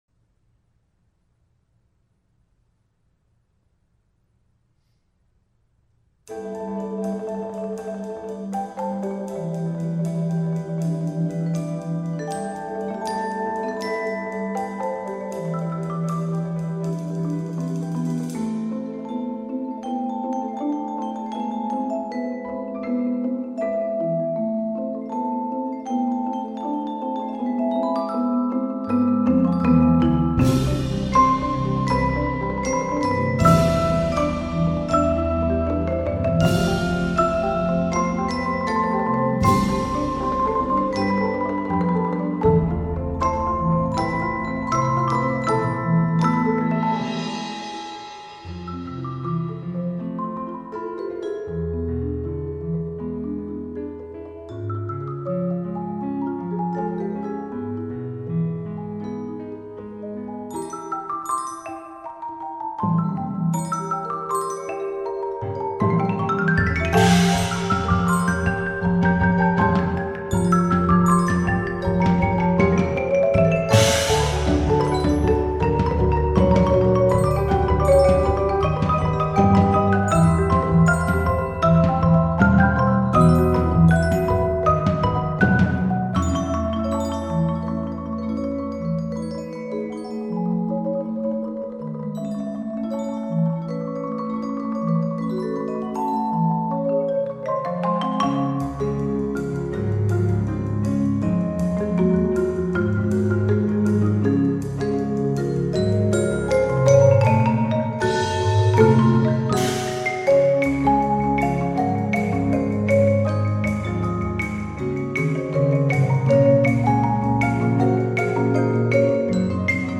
Percussion Ensemble (8-12 players)